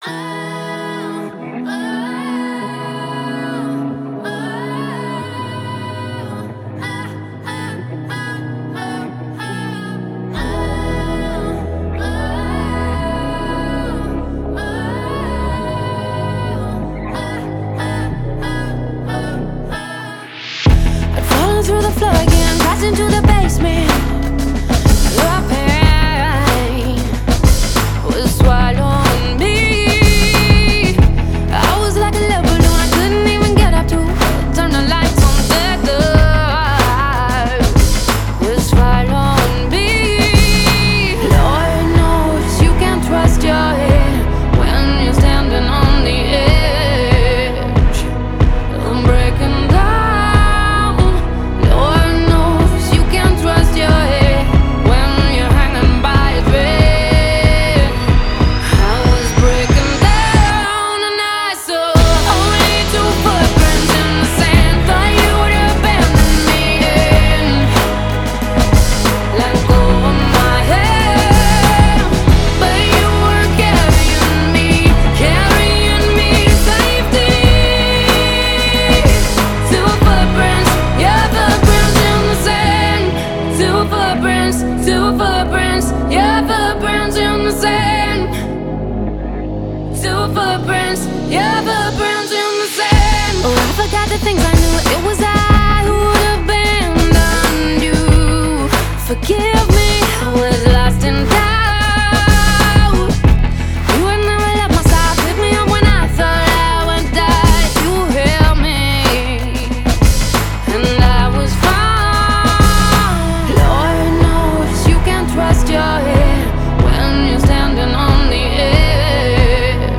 Genre: Alternative, Pop